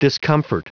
Prononciation du mot discomfort en anglais (fichier audio)
Prononciation du mot : discomfort